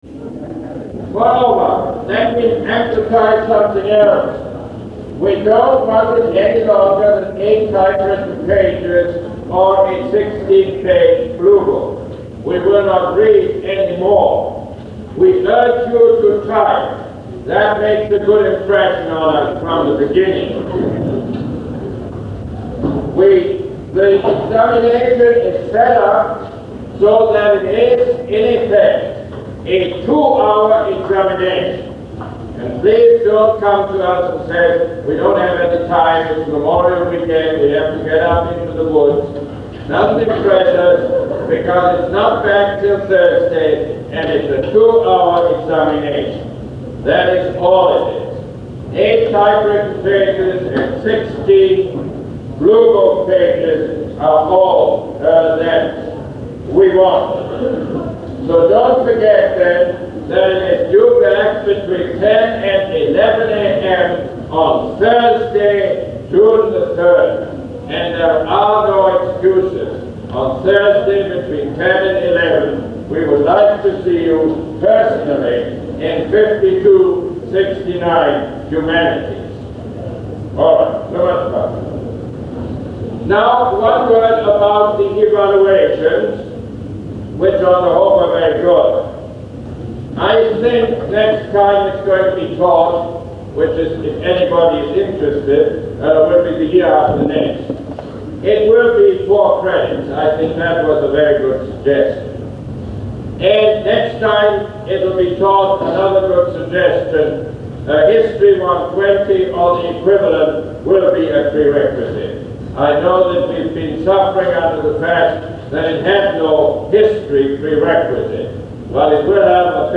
Lecture #30 - May 27, 1971